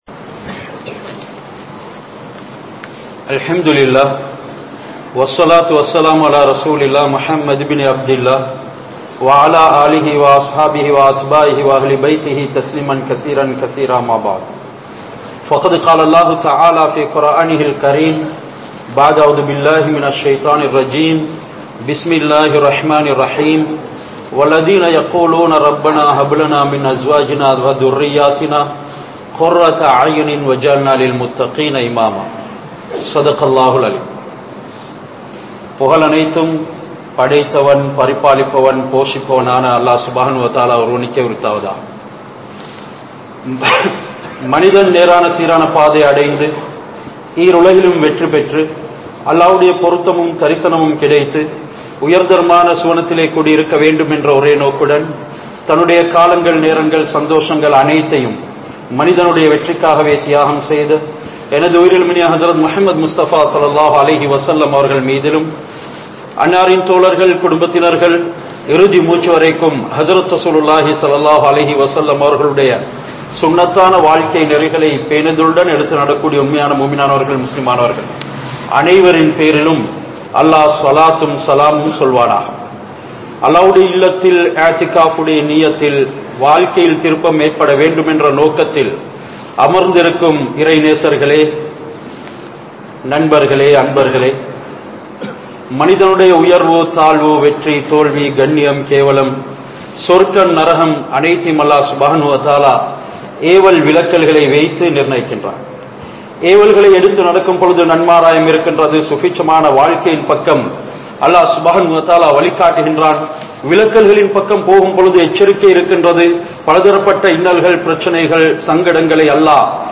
Pillaihalai Valarkum Murai (பிள்ளைகளை வளர்க்கும் முறை) | Audio Bayans | All Ceylon Muslim Youth Community | Addalaichenai
Horuwapathana Jumua Masjidh